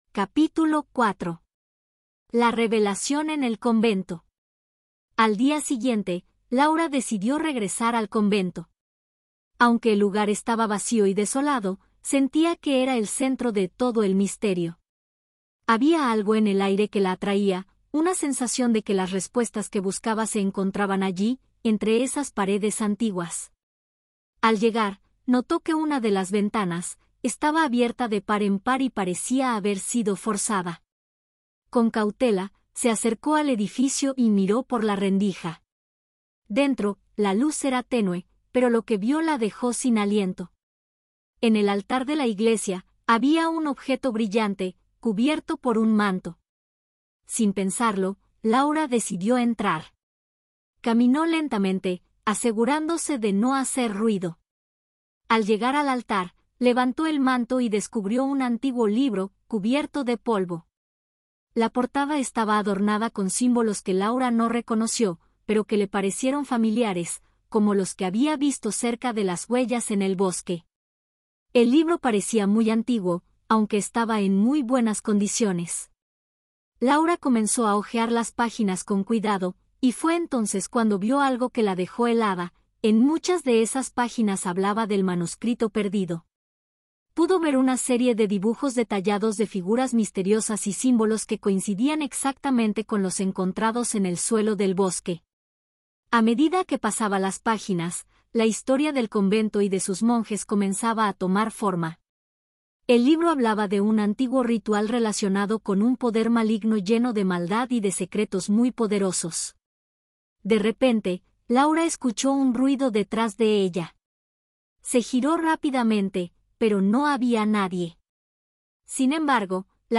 AudioBook El misterio del manuscrito B2-C1 - Hola Mundo